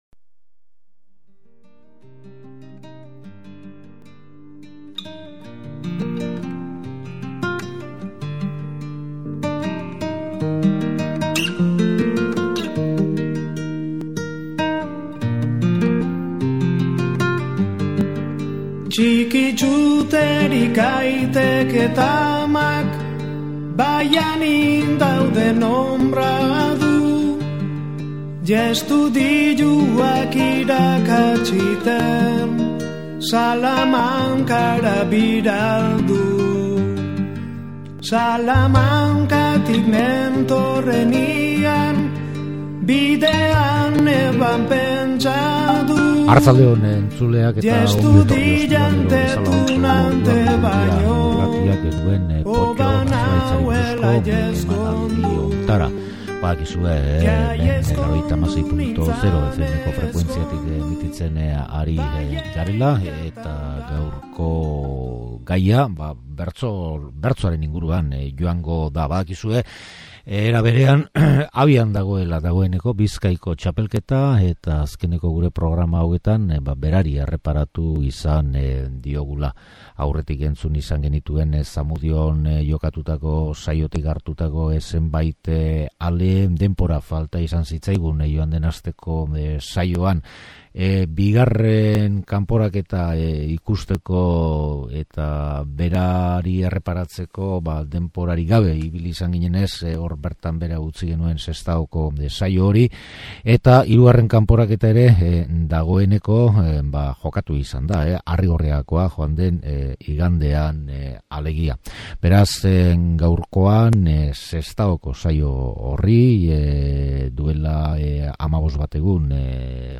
Urriaren 15ean Sestaon egin kanporaketatik bertsolariak zortziko handian, zortziko txikian, puntuari erantzuten eta kartzelako gaian egindako zenbait ale aukeratu ditugu; eta berdin astebete beranduago Arrigorriagan egin saiotik.